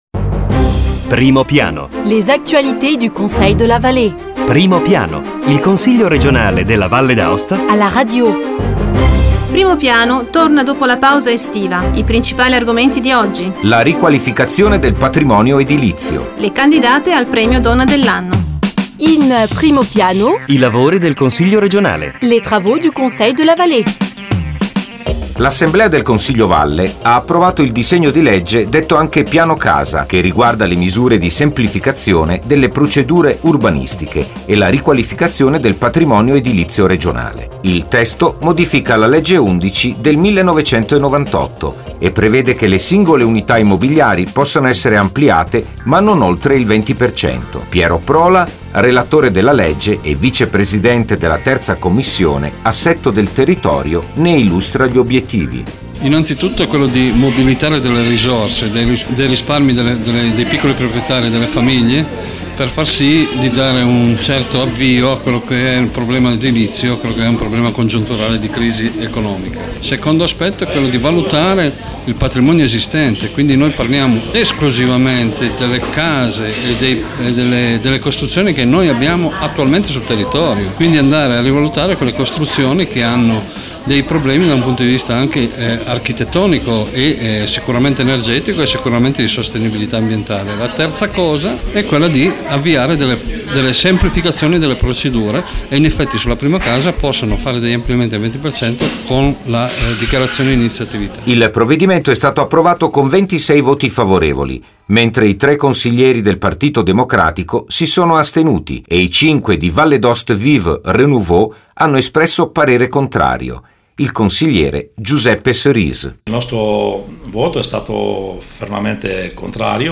Evénements et anniversaires Documents liés 4 septembre 2009 Primo piano Le Conseil r�gional � la radio: aprofondissement hebdomadaire sur l'acitivit� politique, institutionnelle et culturelle de l'assembl�e l�gislative. Voici les arguments de la premi�re transmission apr�s la pause d��t�: le projet de loi "Piano Casa", avec les interviews au Vicepr�sident de la III Commission �Am�nagement du territoire" Piero Prola et au Conseiller du groupe Vall�e d�Aoste Vive/Renouveau, Giuseppe Cerise; la r�union du jury de s�lection du Prix international "La femme de l'ann�e", avec l'interview au Pr�sident du Conseil de la Vall�e, Alberto Cerise.